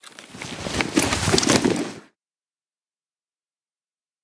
main Divergent / mods / Ledge Climbing Mantling / gamedata / sounds / ledge_grabbing / Vaulting / Medium / medium2.ogg 45 KiB (Stored with Git LFS) Raw Permalink History